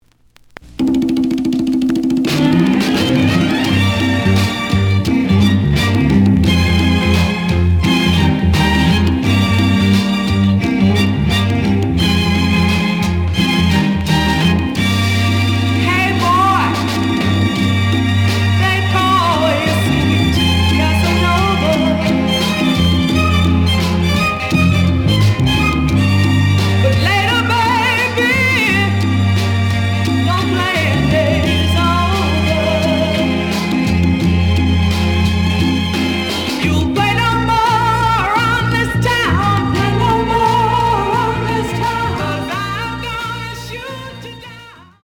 The audio sample is recorded from the actual item.
●Genre: Soul, 70's Soul